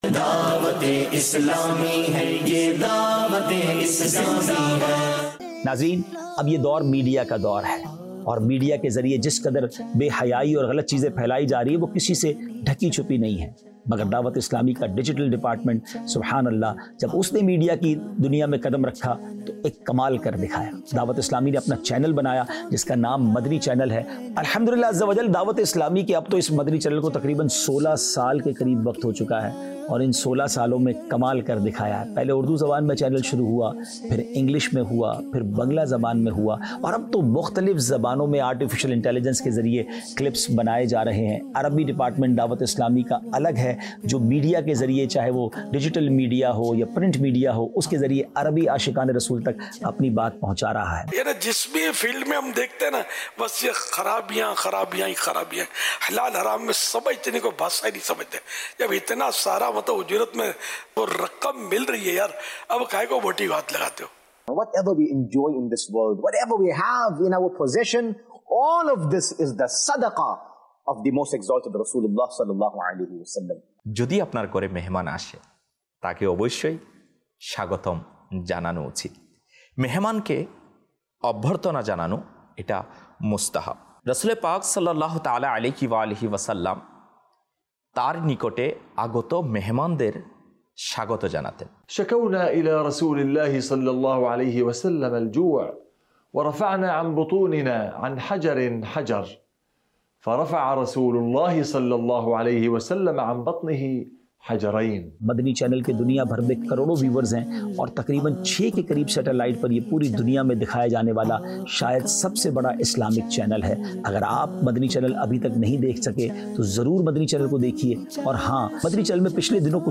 Documentary 2025